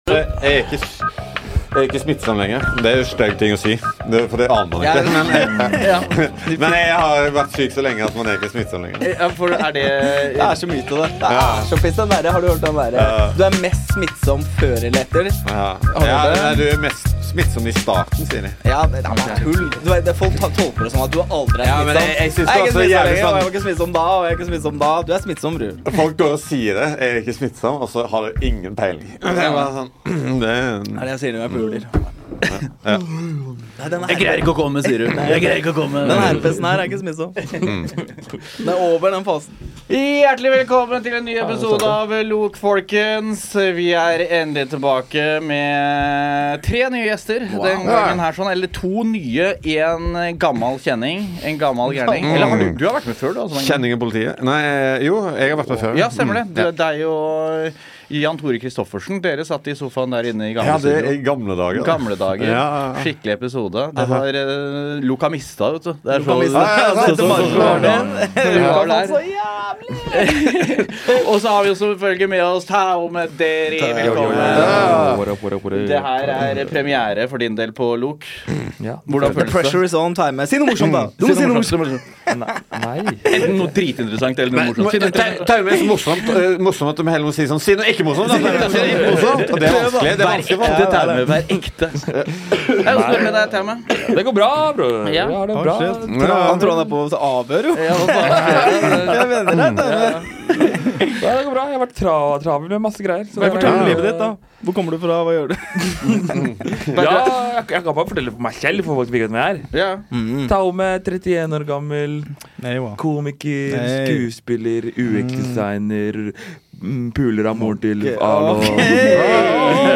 Det er en god blanding folk i studio i dag.